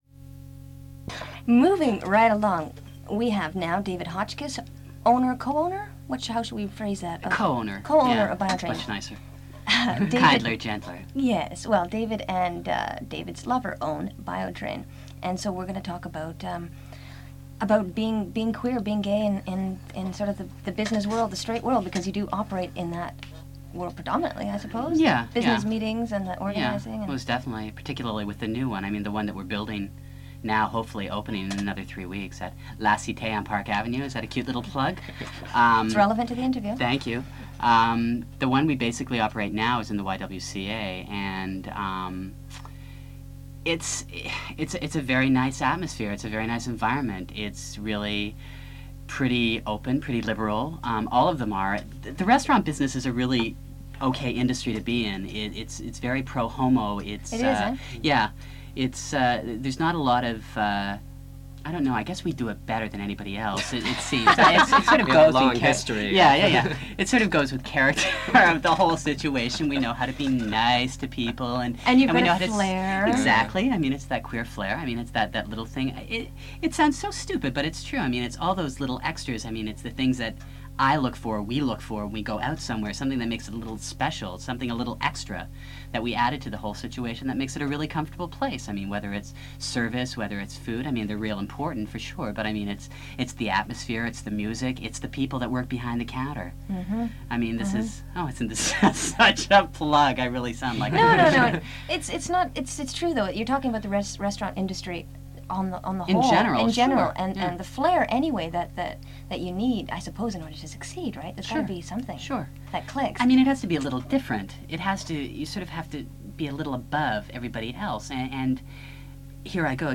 Gay Day was an annual 24 hour broadcast event held by CKUT Radio (which hosted the Dykes on Mykes broadcast), from 1989 to 1991.